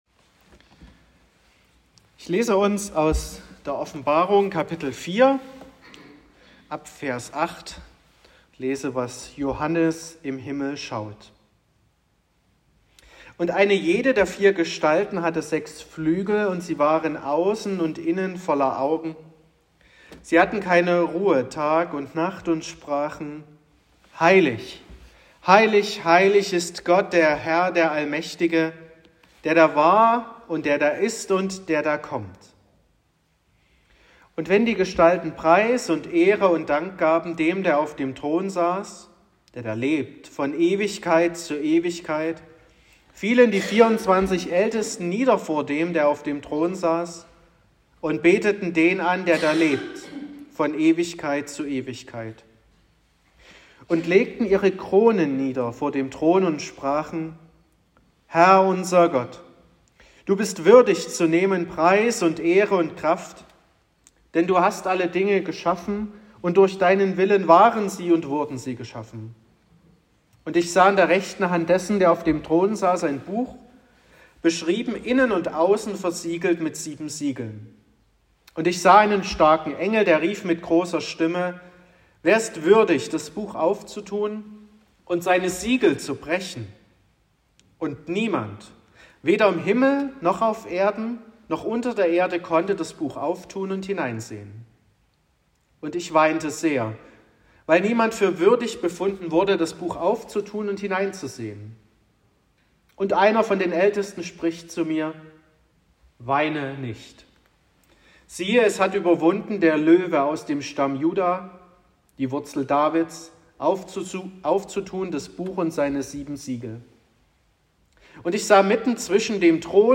11.02.2024 – Gottesdienst
Predigt und Aufzeichnungen
Predigt (Audio): 2024-02-11_Der_Himmel_oeffnet_sich_-_was_ist_Anbetung___Predigtreihe_2024__Thema_2_.m4a (11,1 MB)